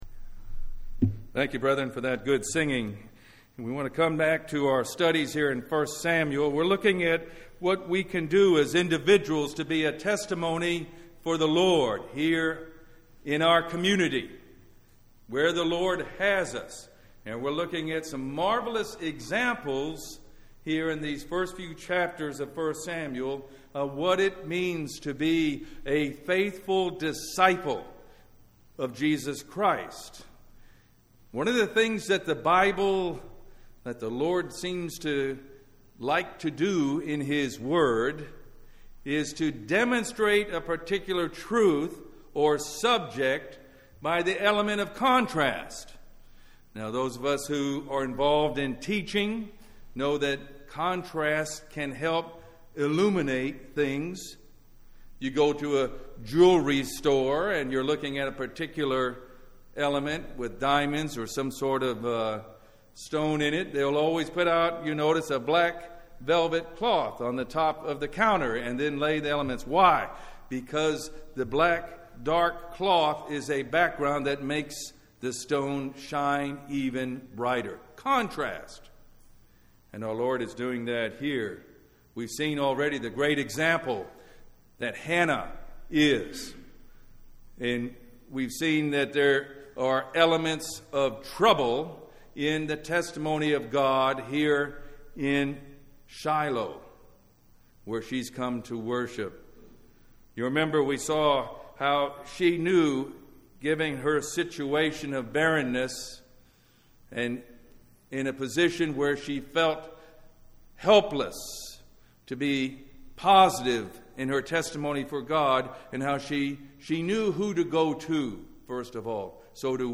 1 Samuel Service Type: Family Bible Hour Bible Text